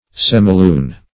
semilune - definition of semilune - synonyms, pronunciation, spelling from Free Dictionary Search Result for " semilune" : The Collaborative International Dictionary of English v.0.48: Semilune \Sem"i*lune`\, n. (Geom.)